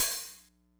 hihat03.wav